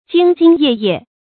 jīng jīng yè yè
兢兢业业发音
成语正音 兢，不能读作“jìnɡ”。